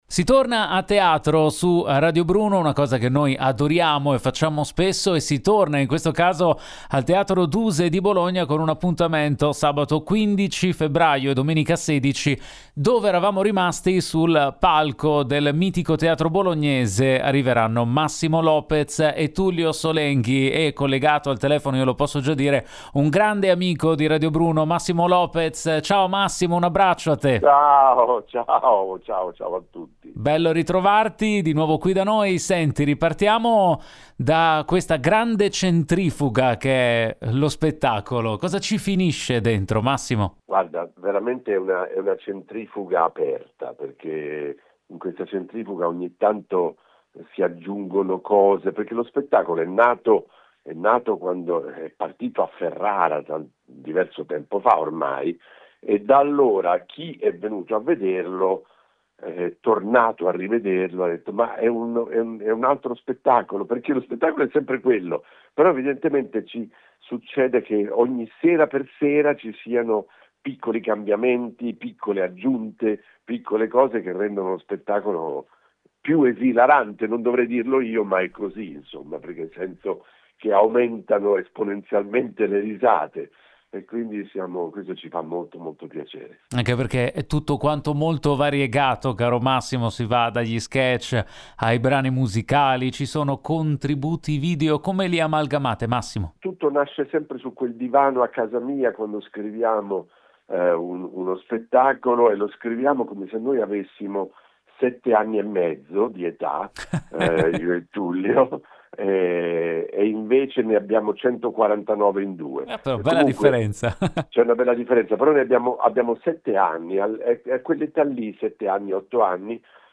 Home Magazine Interviste Massimo Lopez presenta “Dove eravamo rimasti”